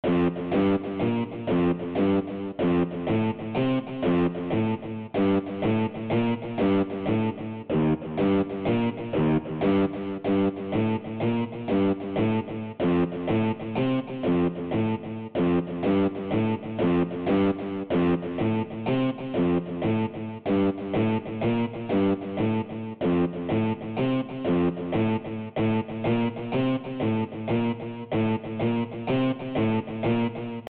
La rythmique : Guitare
Elle est ensuite passée dans l'arpège qui égrène une note toutes les croches pointées
Da_skouer-Rythmic_Guitare.mp3